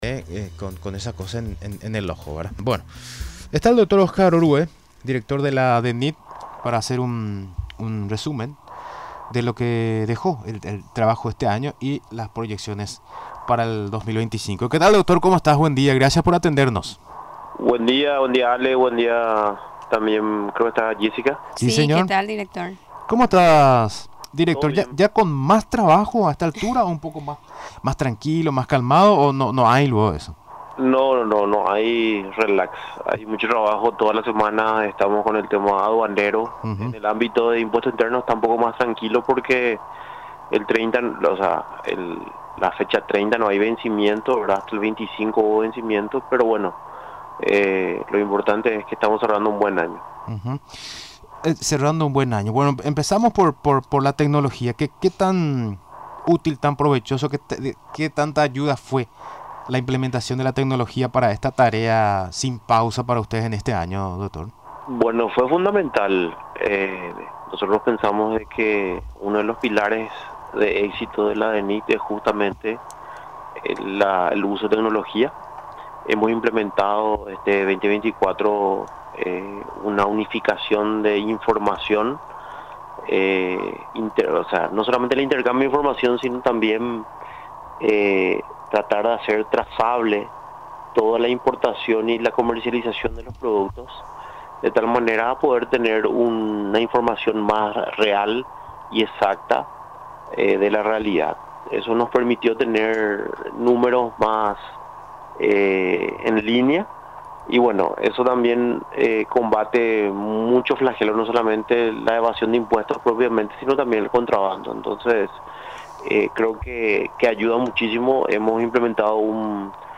Expresó, durante la entrevista en Radio Nacional del Paraguay, estar muy contento, con los resultados. Aseguró que el desafío es que más personas se adhieran a la facturación electrónica.